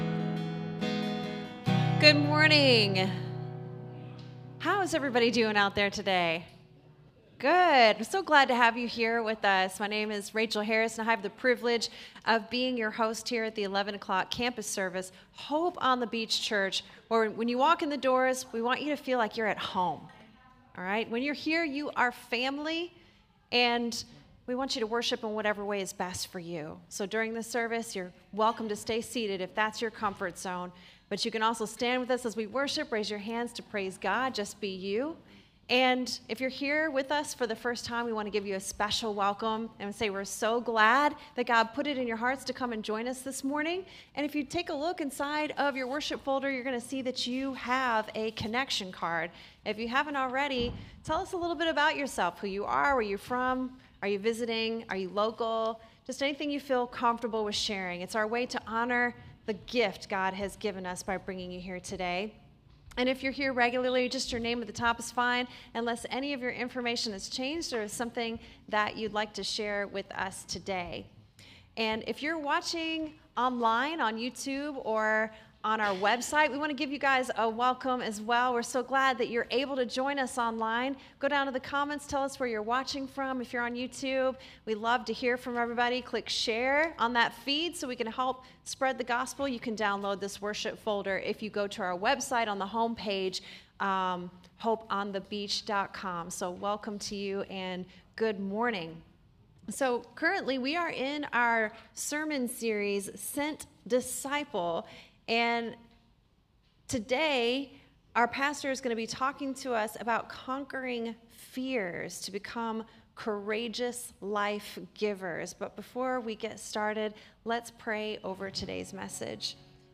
SERMON DESCRIPTION The Good Samaritan showed courage as he helped the beaten man by the road. We need courage to help the hurting people we encounter in our daily lives.